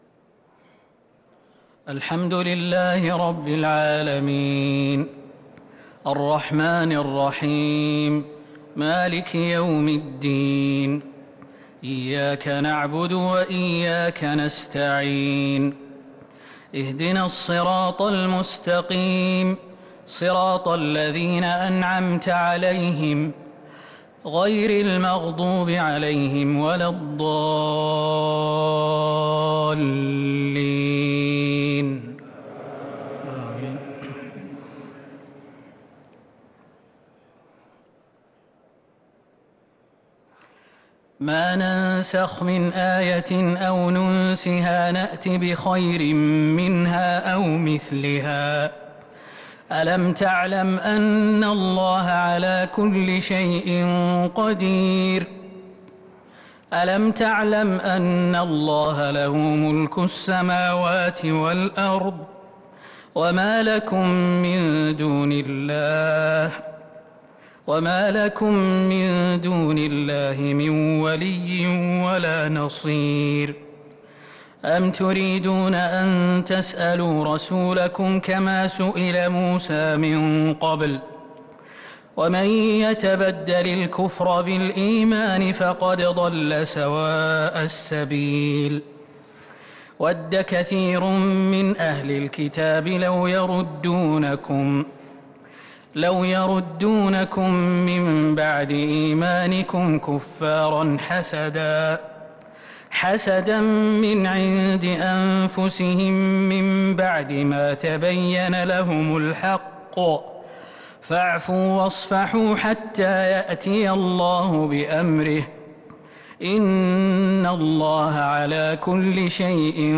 تراويح ليلة 2 رمضان 1443 من سورة البقرة {106-167} Taraweeh 2st night Ramadan 1443H > تراويح الحرم النبوي عام 1443 🕌 > التراويح - تلاوات الحرمين